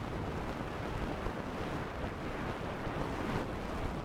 prop_dragging_1.wav